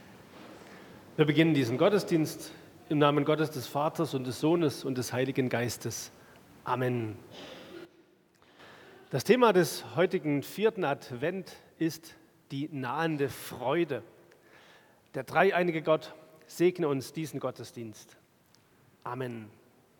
Begrüßung
Audiomitschnitt unseres Gottesdienstes vom 4.Advent 2025.